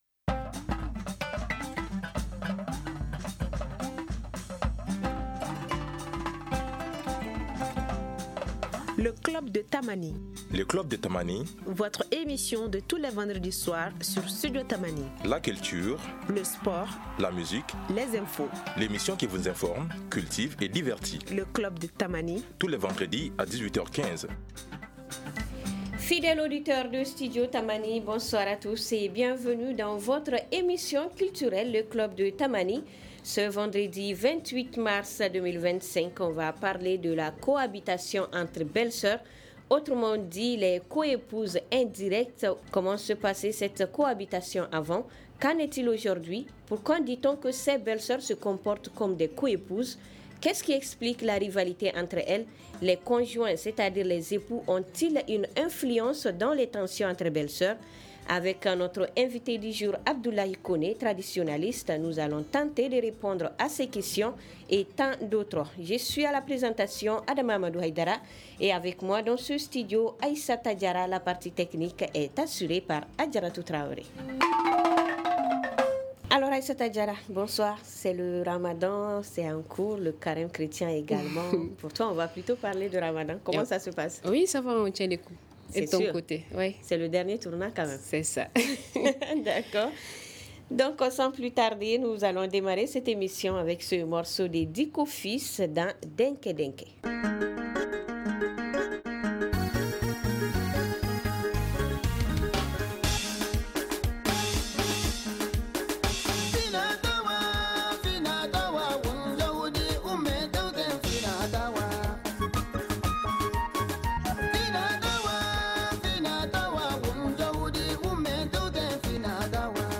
un expert traditionnel.